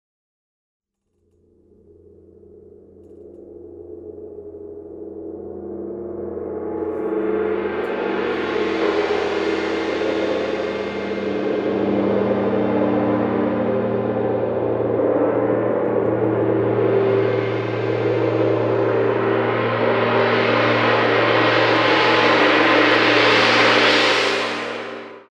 with singing and instrumental music.
marimba, percussion
piano, synthesizer, amadinda
shakhuhatchie, alto flute, ankle bells